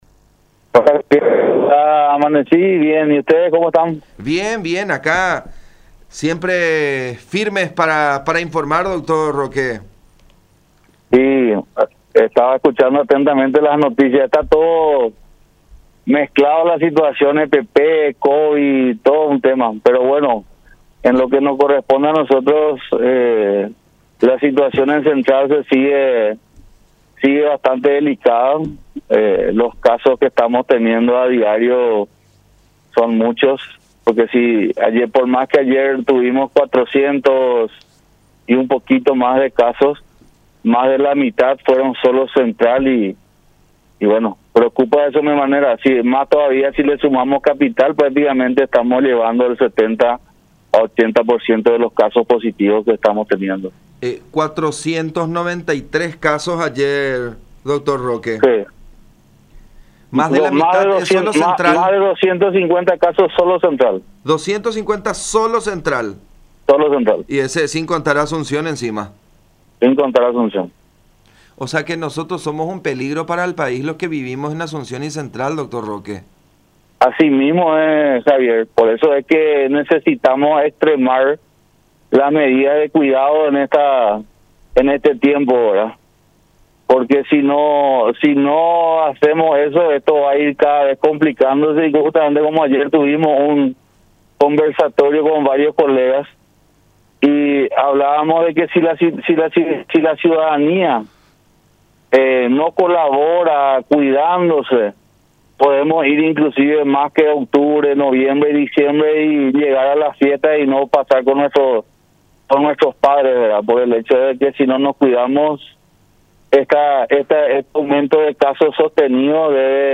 “La situación en el Departamento Central sigue siendo delicada, por el aumento de casos y la verdad que preocupa”, manifestó el Dr. Roque Silva, director de la XI Región Sanitaria, en diálogo con La Unión, indicando que en las últimas semanas esta zona del país, junto con Asunción, forman el epicentro actual de contagios de coronavirus en nuestro país.